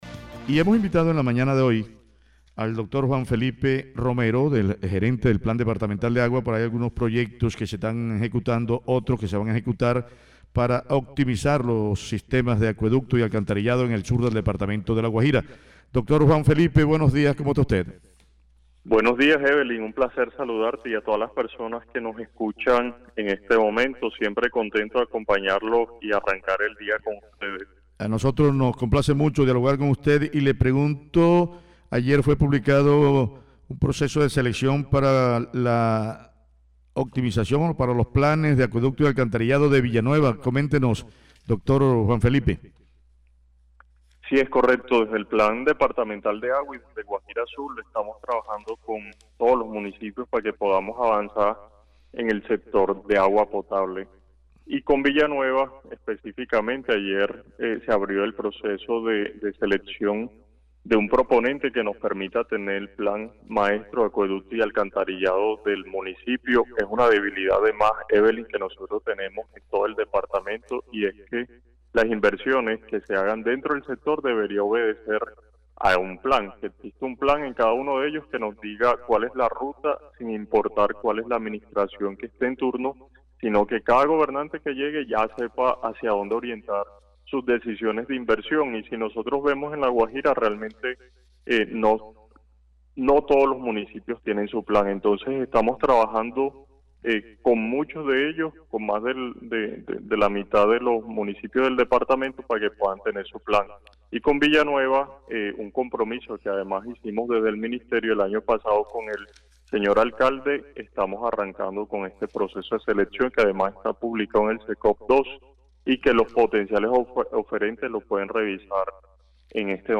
Voz-Juan-Felipe-Romero-–-Plan-departamental-de-agua.mp3